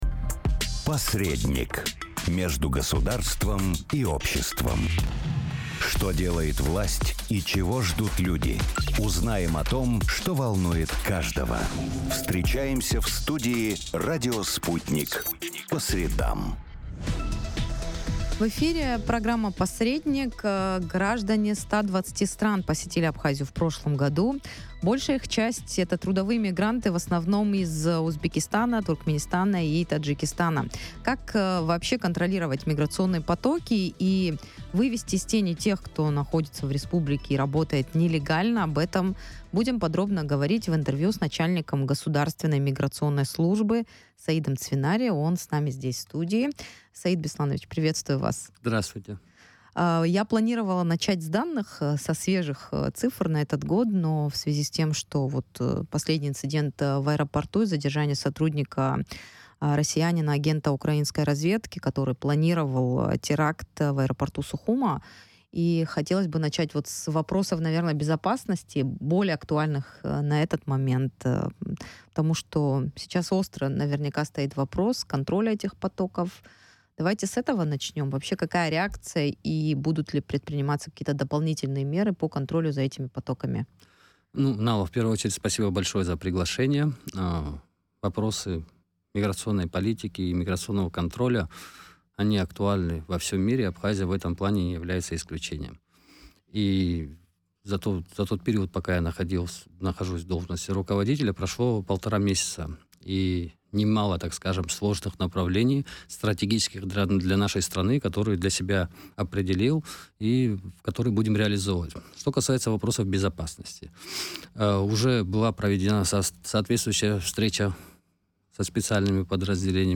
Граждане 120 стран посетили Абхазию в прошлом году, большая часть из них — это трудовые мигранты, в основном из Узбекистана, Туркменистана и Таджикистана. Как контролировать миграционные потоки и вывести из тени тех, кто находится в республике и работает нелегально, об этом в эфире радио Sputnik рассказал начальник Государственной миграционной службы Саид Цвинария.